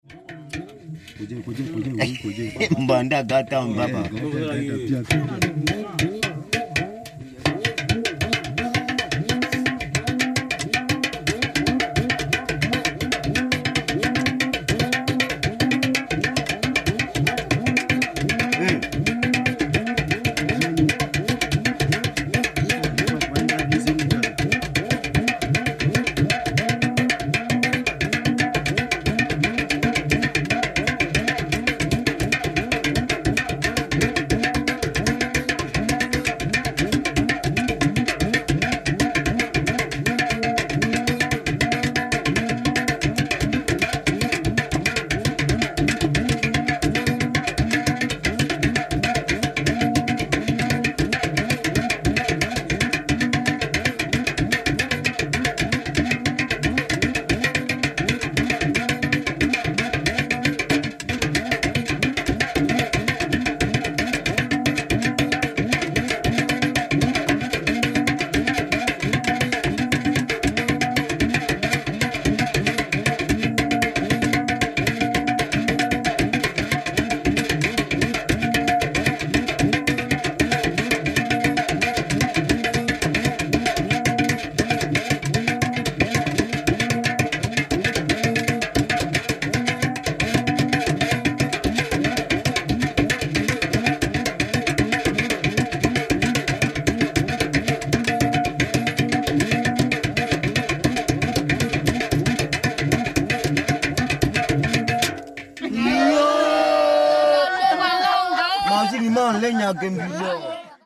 bubulu (pot bow)
cassette tape and digital audio tape recordings of Bayaka music and soundscapes